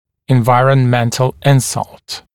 [ɪnˌvaɪərən’mentl ‘ɪnsʌlt]] [en-] [инˌвайэрэн’мэнтл ‘инсалт] [эн-] поражение, вызванное внешними факторами
environmental-insult.mp3